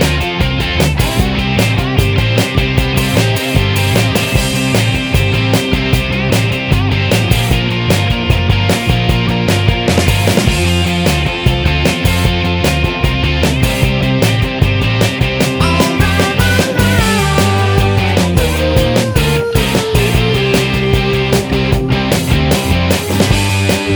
No Piano Pop (1970s) 4:43 Buy £1.50